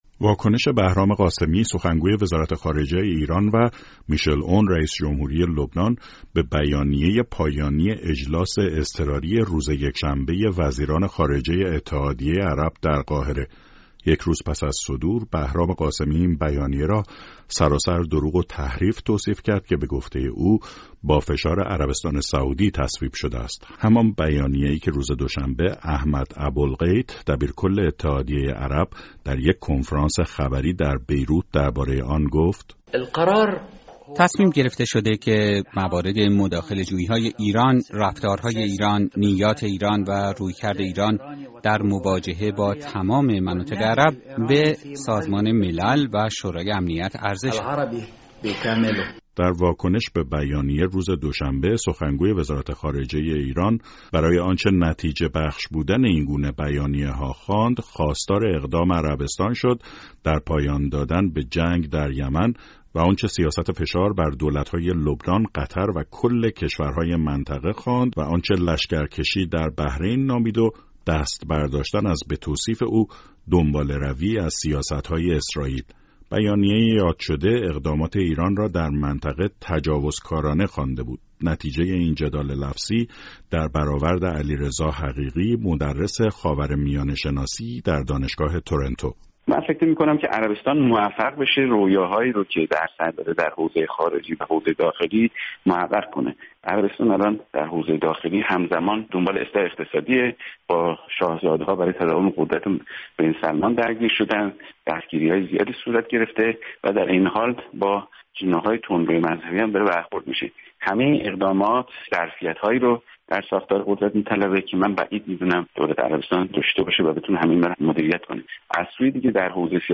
دو کارشناس مناسبات خارجی ایران و روابط بین الملل در گفت‌وگو با رادیو فردا واکنشهای ایران و لبنان به بیانیه روز یکشنبه وزیران خارجه اتحادیه عرب و پاسخ دبیرکل این اتحادیه را بررسی کرده‌اند.